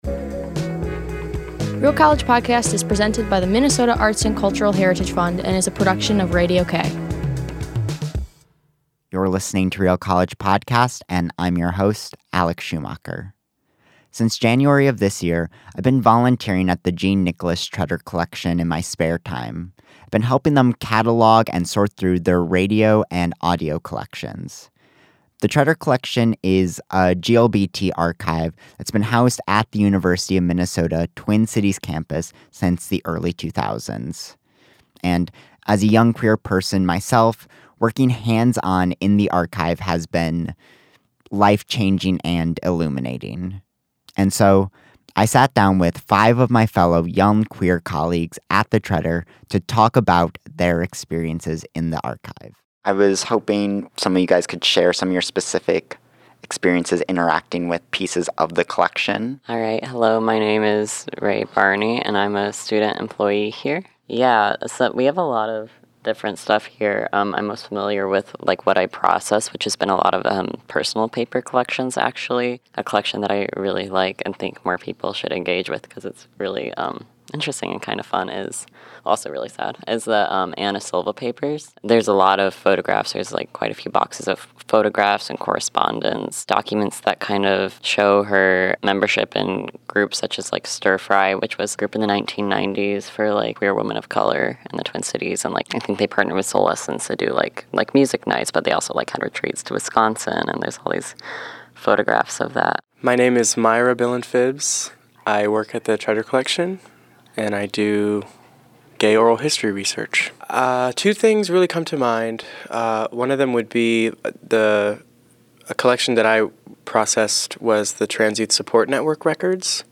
Archiving Queer History Roundtable: Real College Podcast